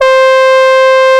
70 BRASS.wav